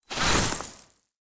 Free Frost Mage - SFX
ice_teleport_in_04.wav